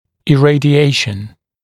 [ɪˌreɪdɪ’eɪʃn][иˌрэйди’эйшн]облучение, химиотерапия